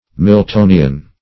Meaning of miltonian. miltonian synonyms, pronunciation, spelling and more from Free Dictionary.
Miltonian \Mil*to"ni*an\